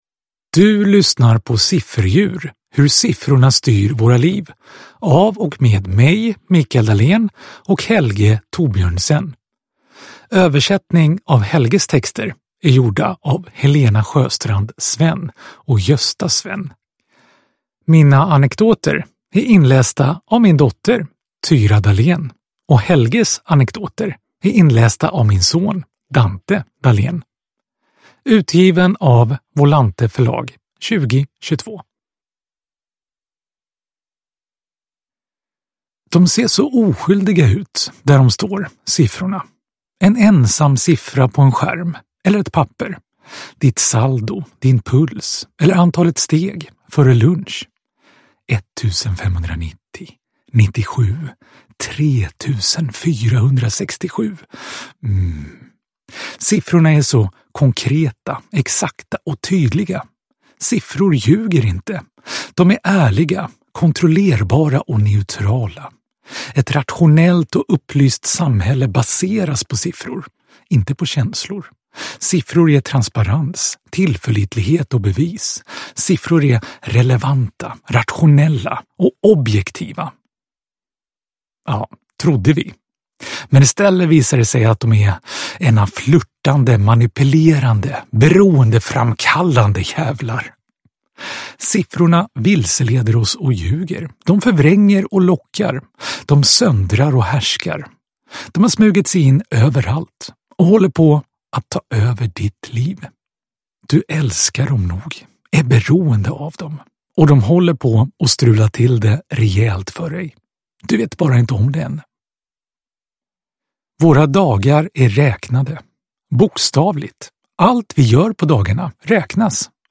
Sifferdjur : Hur siffrorna styr våra liv – Ljudbok – Laddas ner